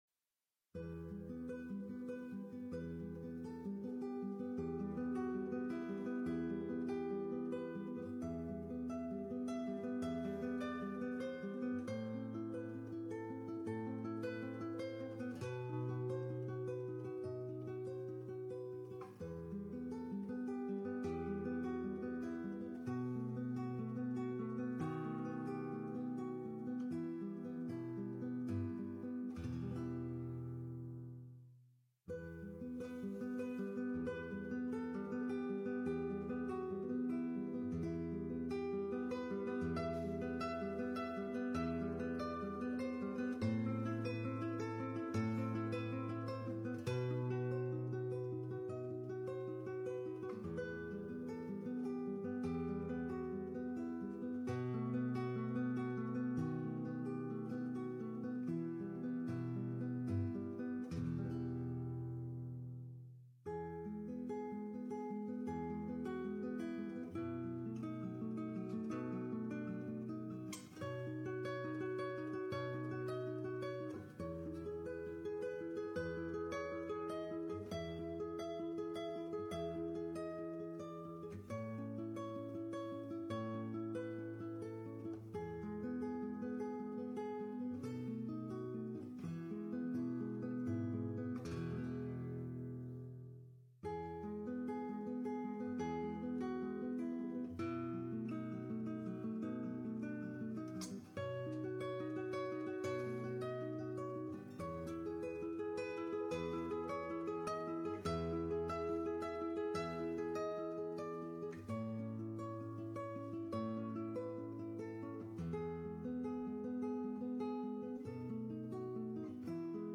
guitar
as we waited in line for the opening of The Prado in Madrid